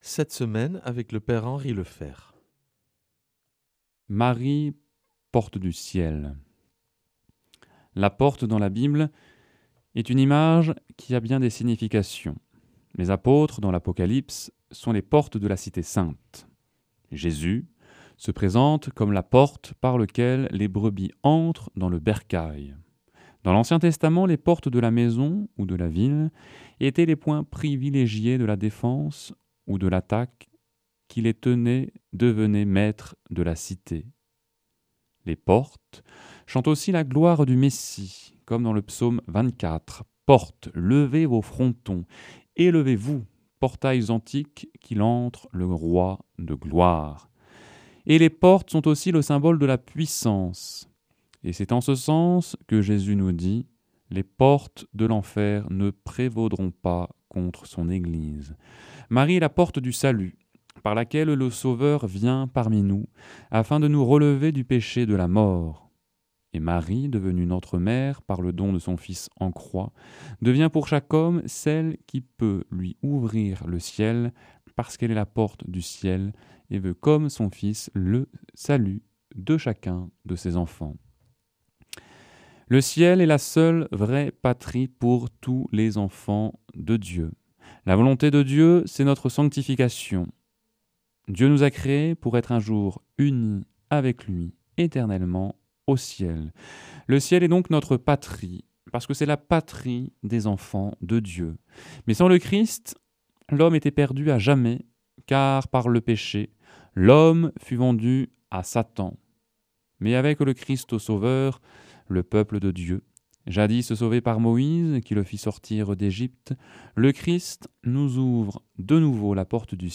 vendredi 15 août 2025 Enseignement Marial Durée 10 min